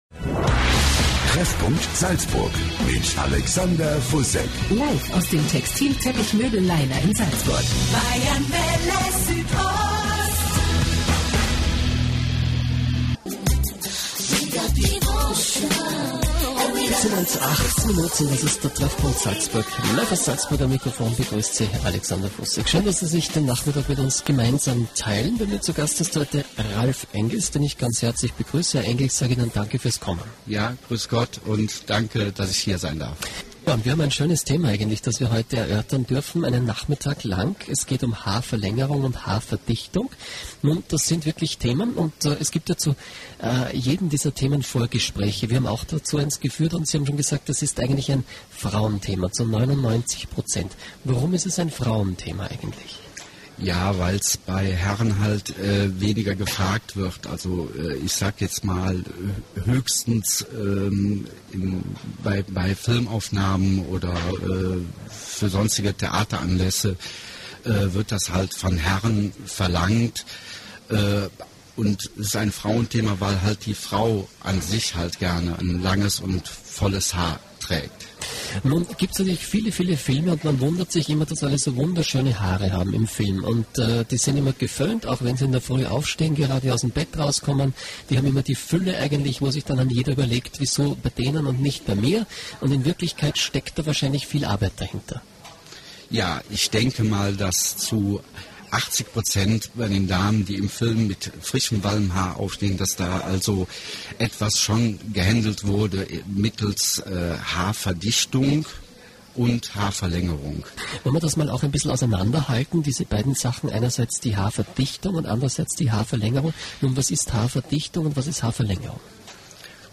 Radiointerview
interview-radio.mp3